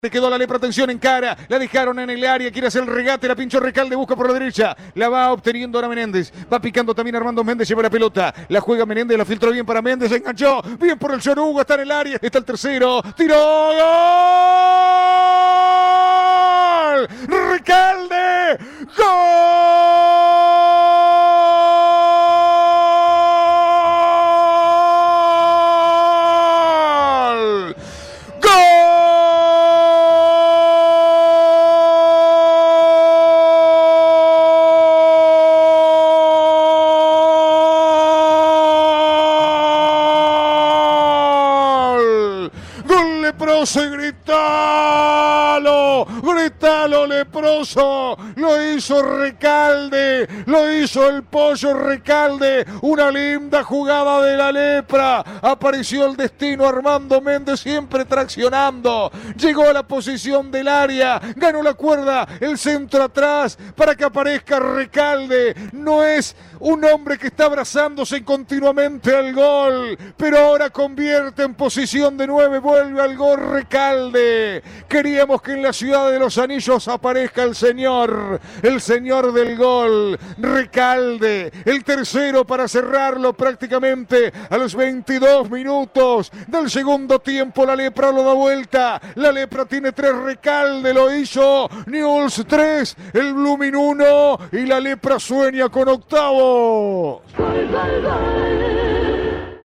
relato